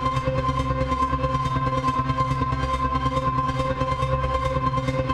Index of /musicradar/dystopian-drone-samples/Tempo Loops/140bpm
DD_TempoDroneB_140-C.wav